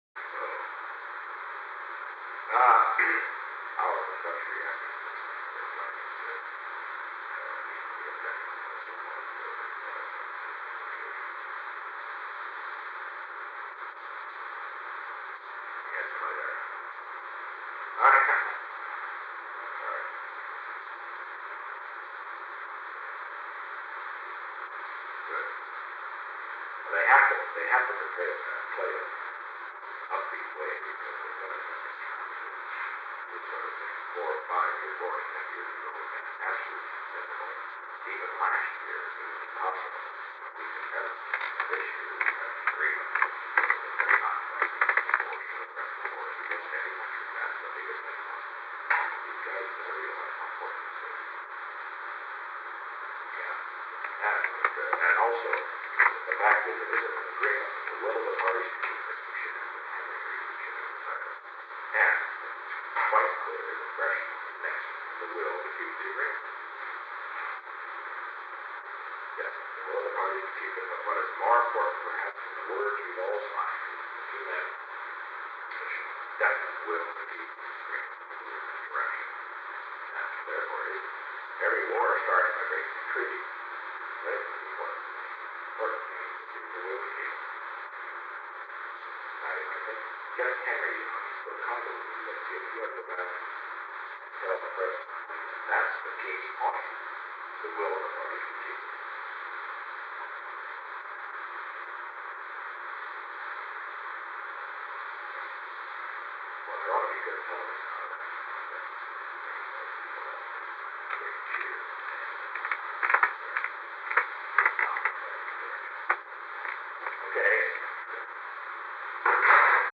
Secret White House Tapes
Location: Executive Office Building
The President talked with Ronald L. Ziegler